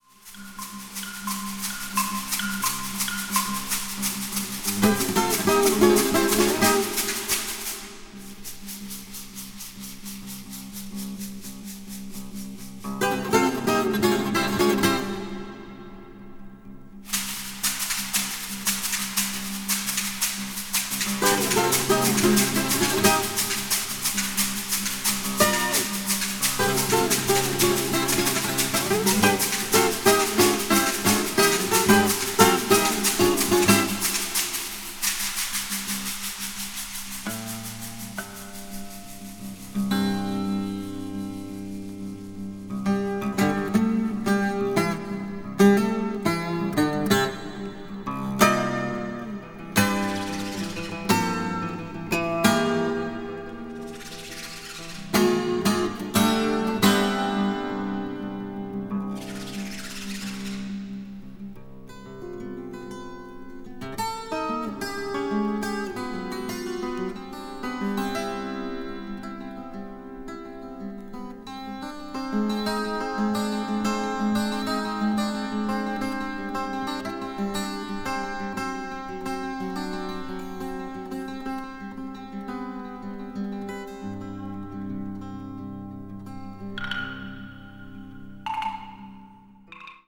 media : NM-/NM-(some svery lightly noises.)